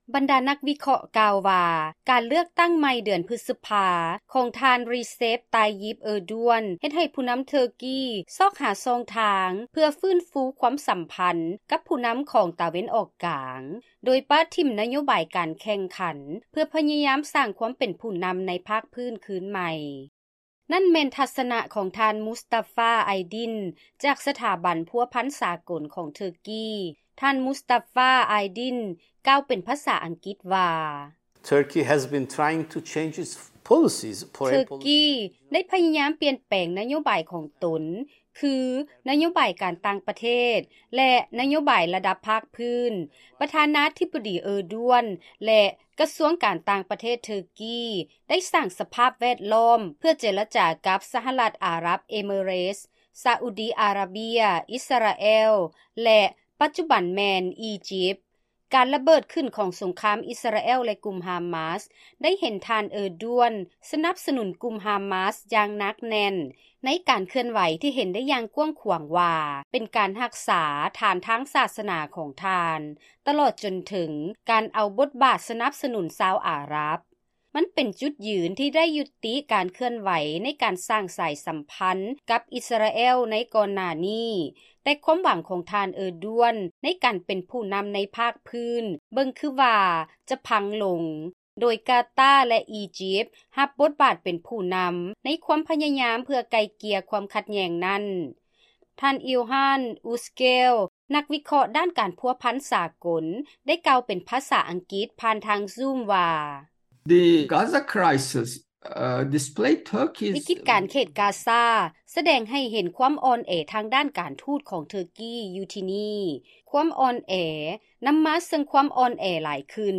ເຊີນຮັບຟັງລາຍງານ ກ່ຽວກັບປະທານາທິບໍດີ ເອີດວນ ຂອງເທີກີ ໃຊ້ອຳນາດການເລືອກຕັ້ງຄັ້ງໃໝ່ ເພື່ອໃຫ້ເປັນຜູ້ນໍາລະດັບພາກພື້ນ